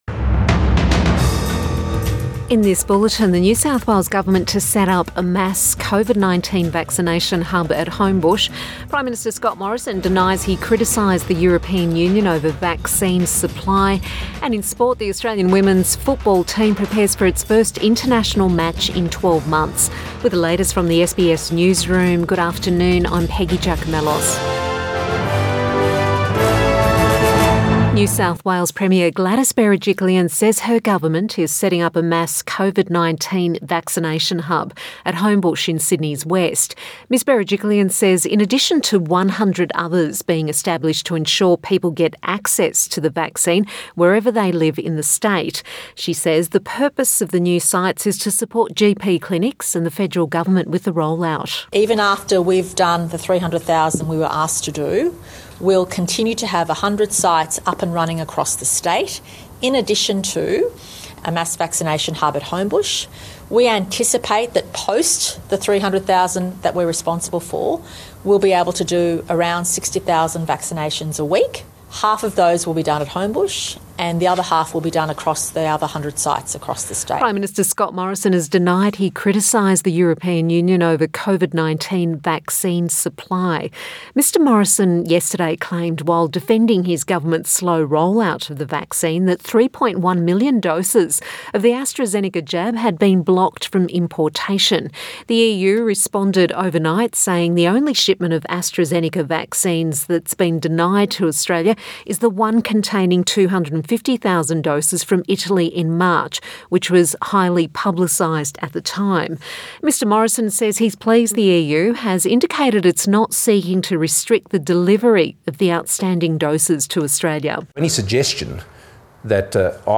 PM bulletin 7 April 2021